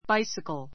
báisikl